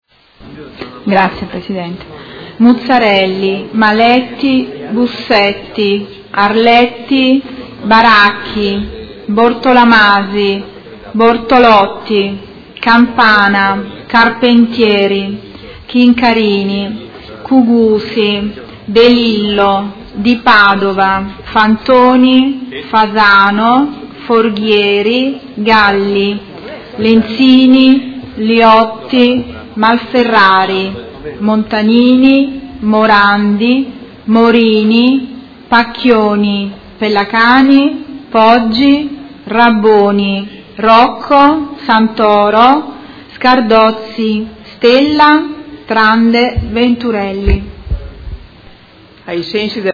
Seduta del 20/07/2017 Appello.
Segretaria